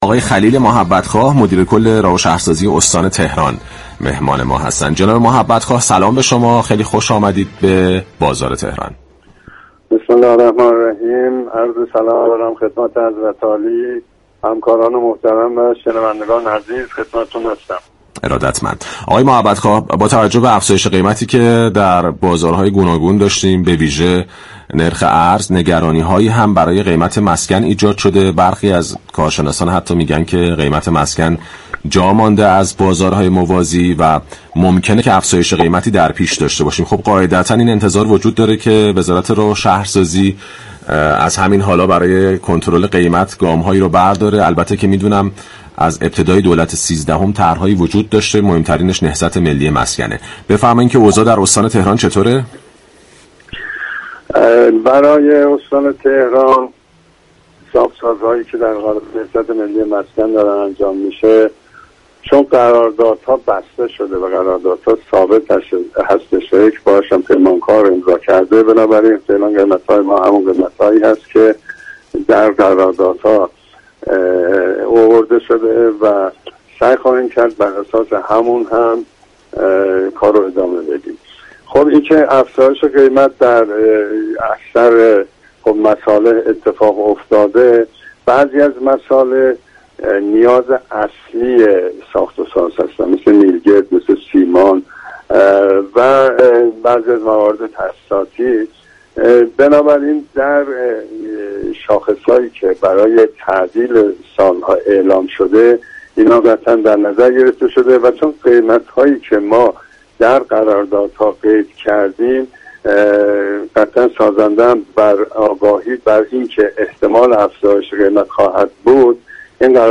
به گزارش پایگاه اطلاع رسانی رادیو تهران، خلیل محبت خواه مدیركل راه و شهرسازی استان تهران در گفت و گو با «بازار تهران» 7 دی در پاسخ به این پرسش كه آیا قیمت واحدهای مسكونی طرح نهضت ملی مسكن با توجه به تورم و گرانی افزایش خواهد داشت یا خیر؟